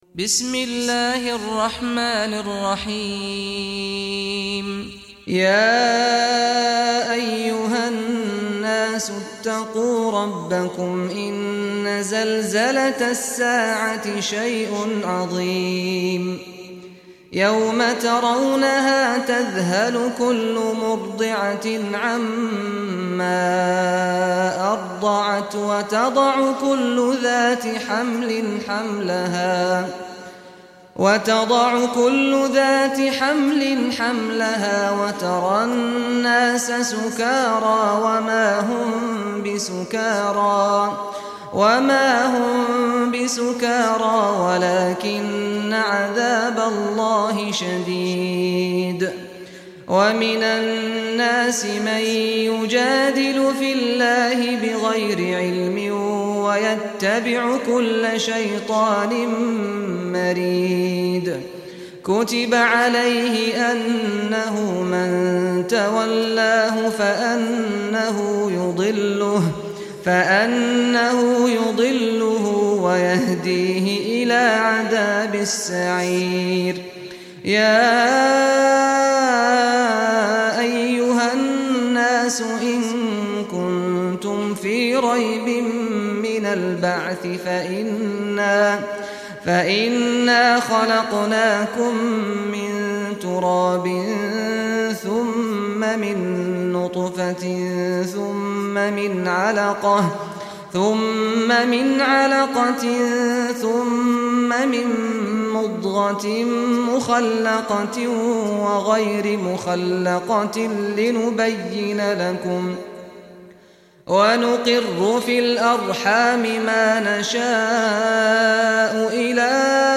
Surah Hajj Recitation by Sheikh Saad al Ghamdi
Surah Hajj, listen or play online mp3 tilawat / recitation in Arabic in the beautiful voice of Imam Sheikh Saad al Ghamdi.
22-surah-hajj.mp3